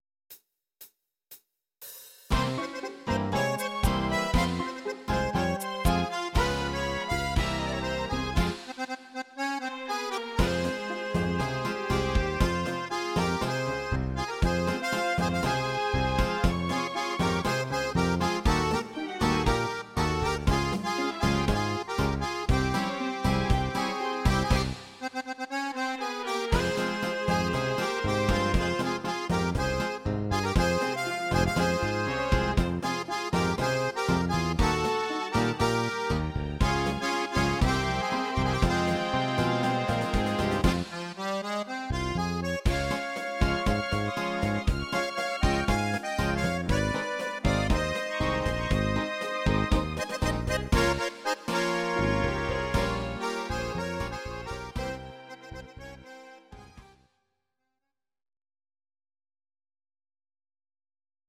These are MP3 versions of our MIDI file catalogue.
Please note: no vocals and no karaoke included.
Akkordeon tango